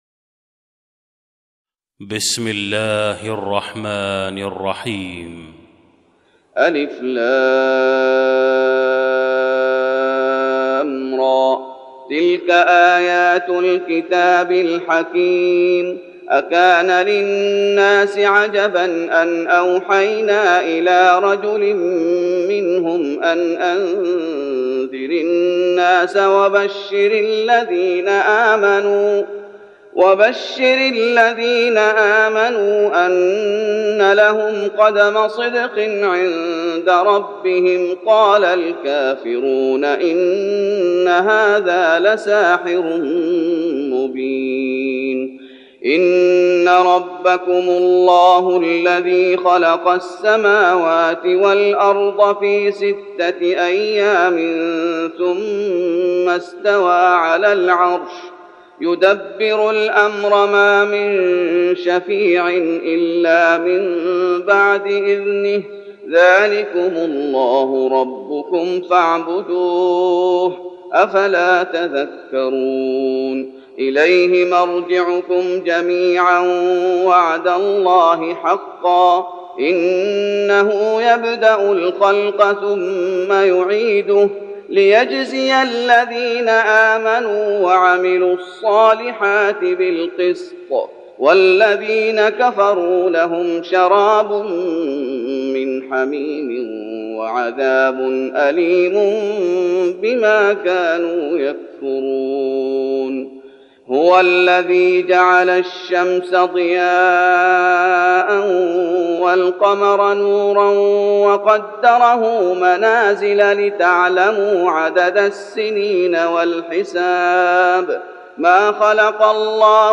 تهجد رمضان 1412هـ من سورة يونس (1-24) Tahajjud Ramadan 1412H from Surah Yunus > تراويح الشيخ محمد أيوب بالنبوي 1412 🕌 > التراويح - تلاوات الحرمين